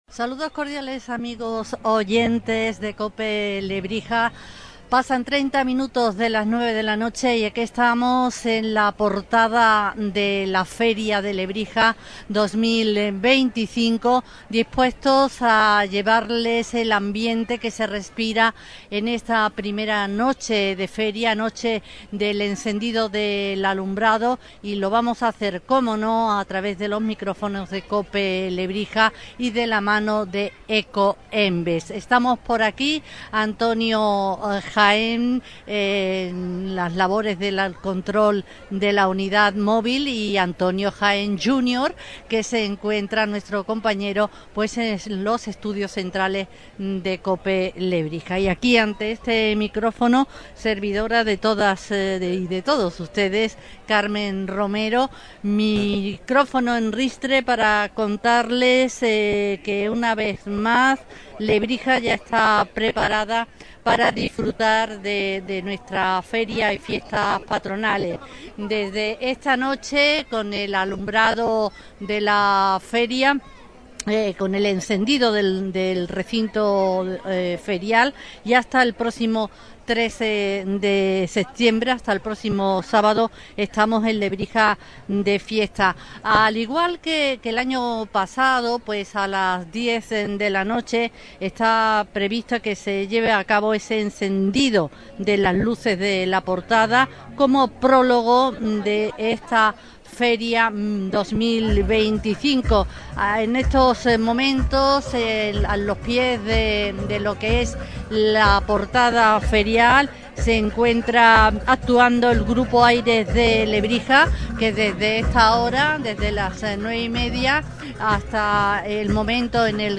Directos Feria 2025